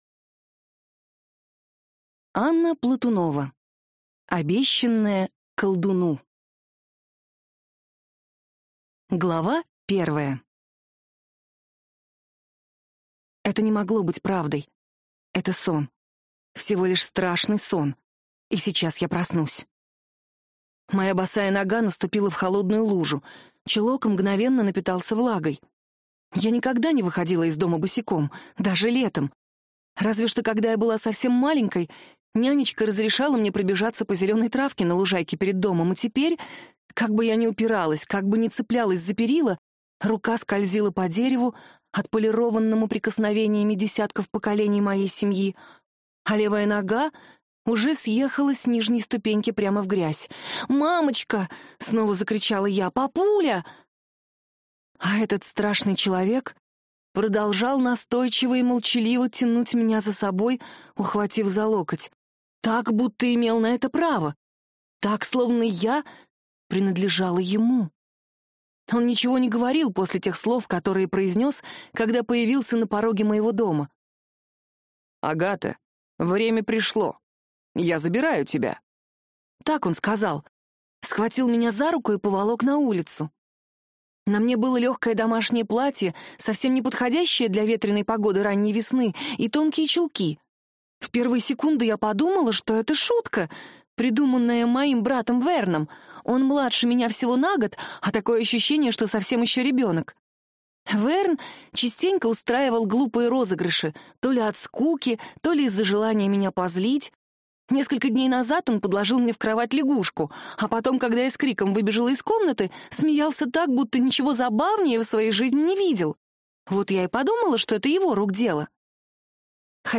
Аудиокнига Обещанная колдуну | Библиотека аудиокниг